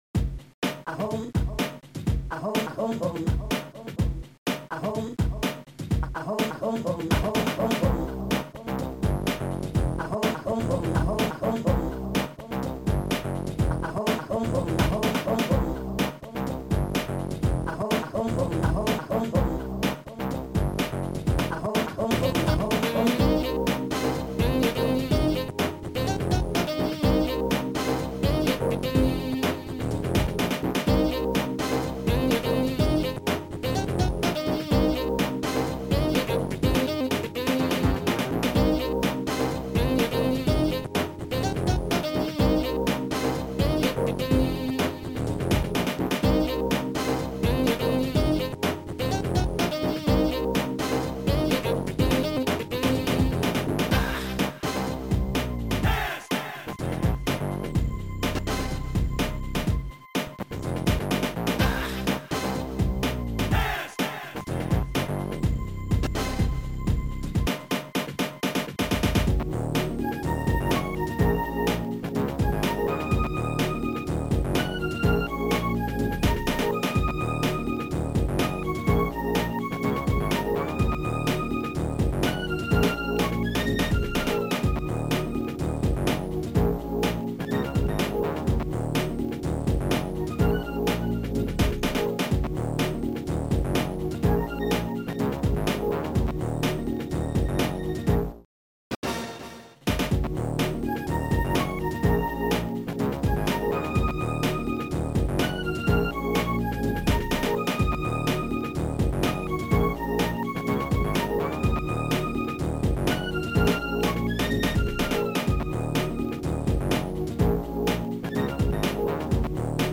Sound Format: Noisetracker/Protracker
Sound Style: Groovy